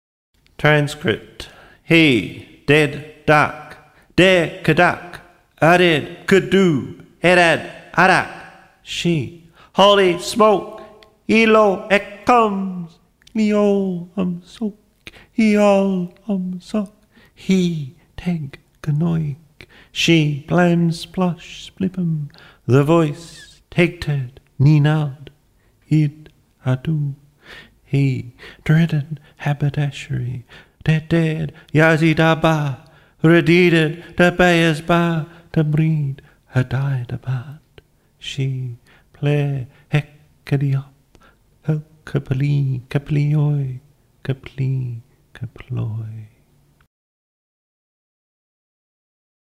sound poems
My kind of sound poetry often involves variations on words or statements. I mix obvious jokes, metaphysical word games, one-actor mini-plays in which words break down with an exploration of how sound moves through the body and how the body moves with sound.